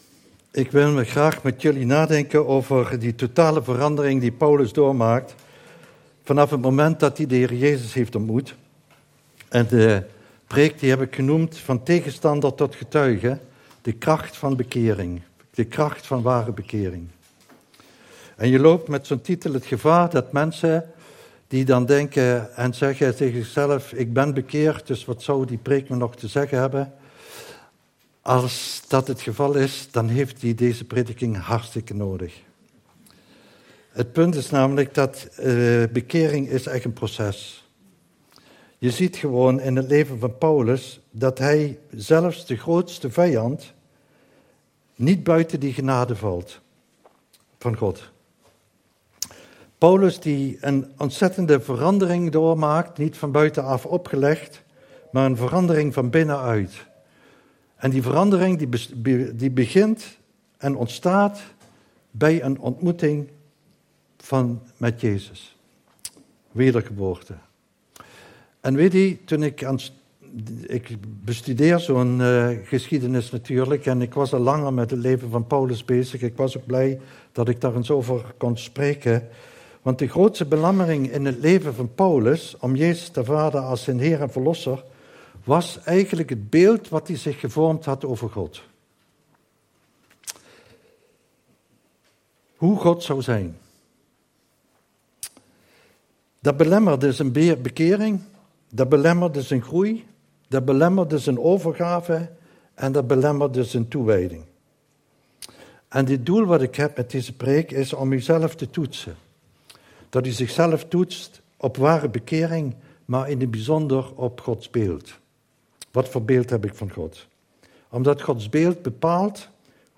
Passage: Handelingen 9:1-22 Dienstsoort: Eredienst